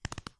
Horse Gallop 6.wav